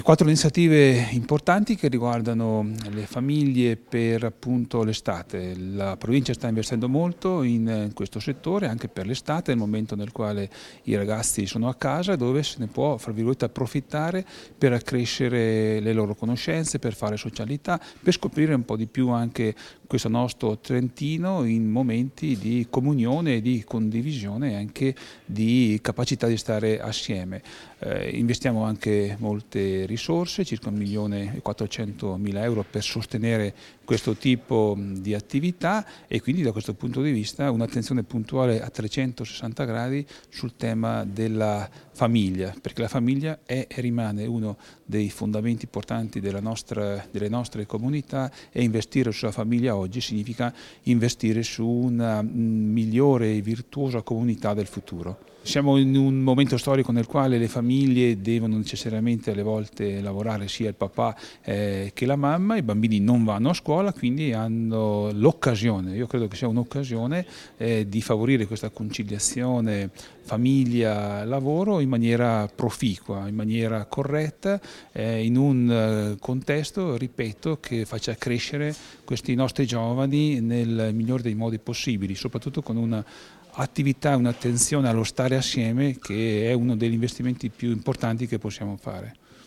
La conferenza stampa si è tenuta stamattina presso la Sala Rosa del Palazzo della Regione